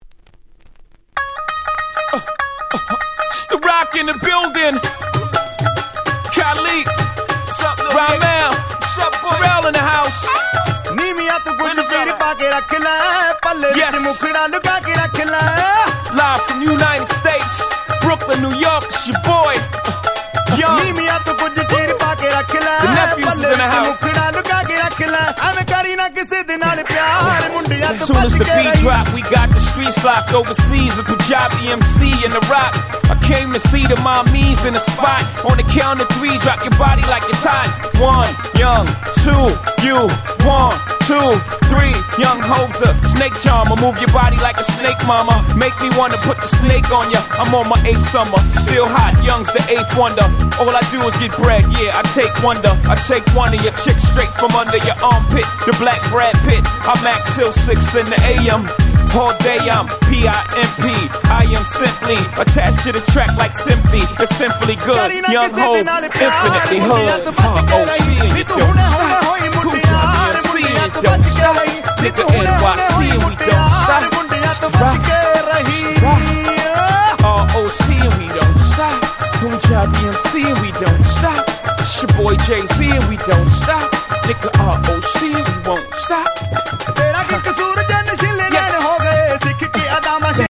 超絶Club Hit 00's Hip Hop !!!!!